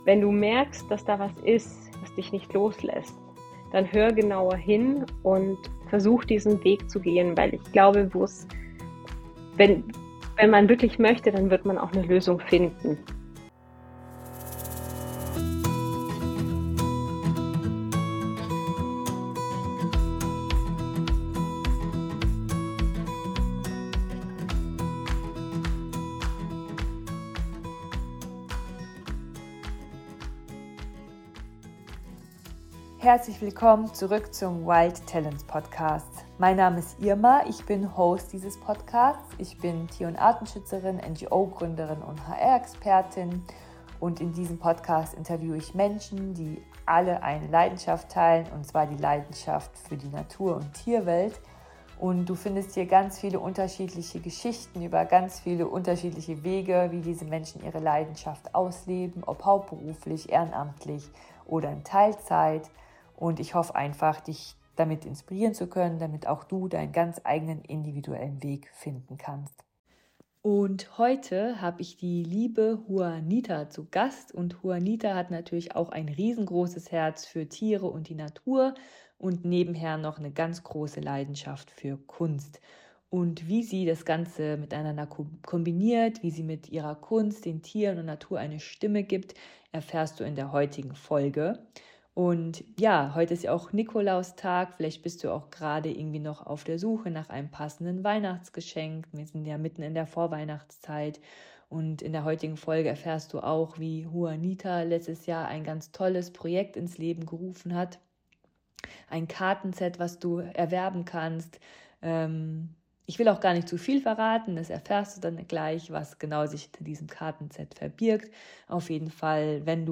#9 Zwischen Pinsel und Pfoten: Kunst für den Tierschutz - Interview